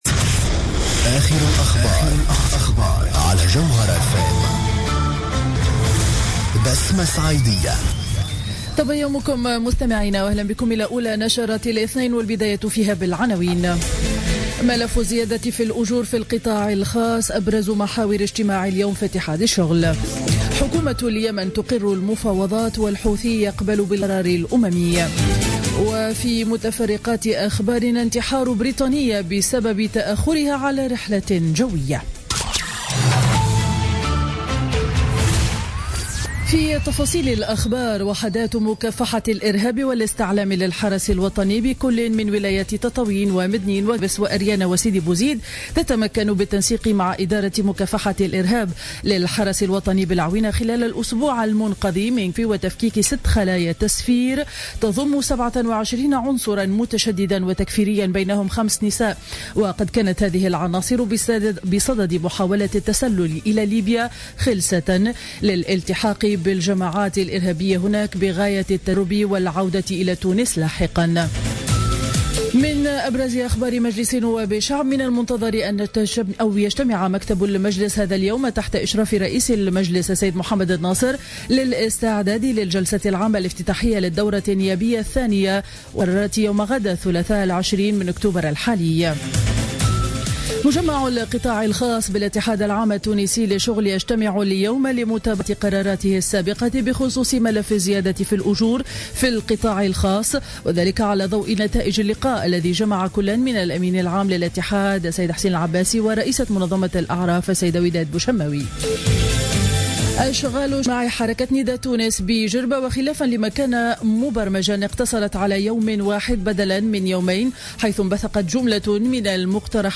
نشرة أخبار السابعة صباحا ليوم الاثنين 19 أكتوبر 2015